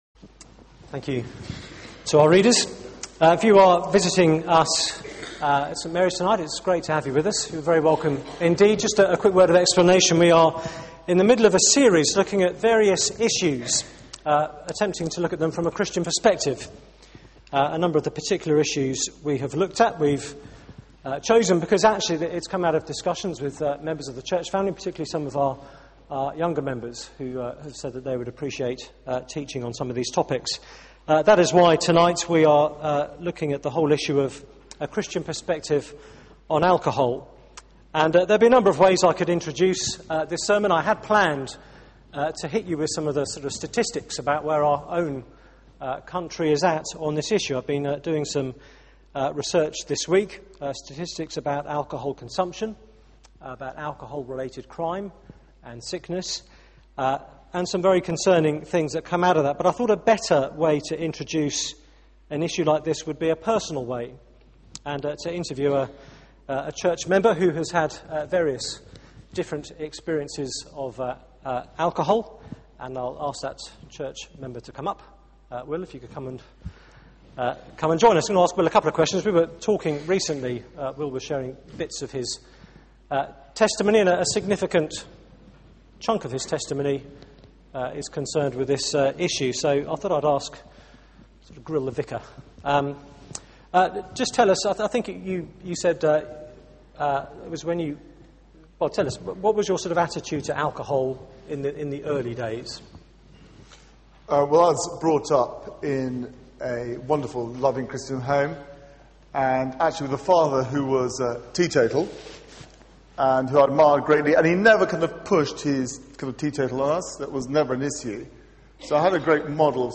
Media for 6:30pm Service on Sun 18th Sep 2011
Theme: A distinctive view of alcohol Sermon